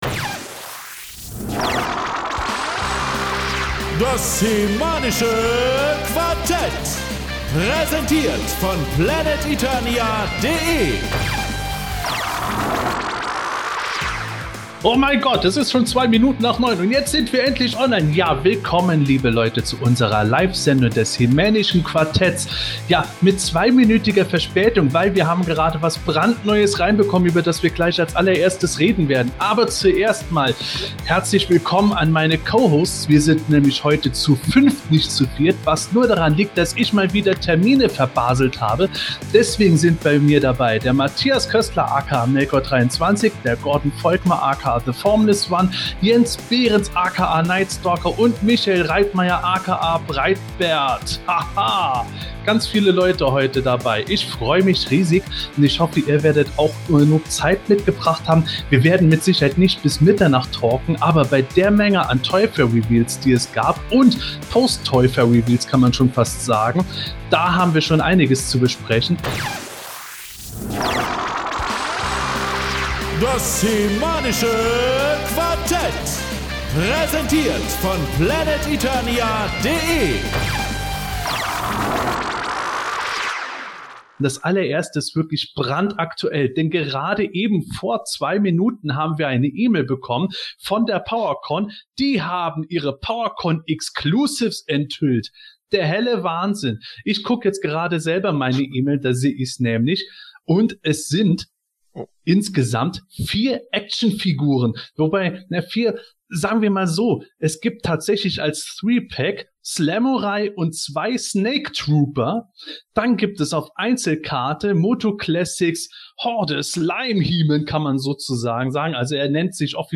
Leider hat sich die Technik hier verselbstständig und hat uns einen Streich gespielt.